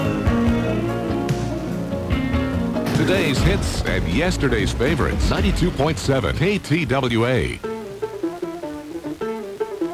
In addition to the stations shown in the local dial guide these were received this morning at about 7:30 – 8:10 AM.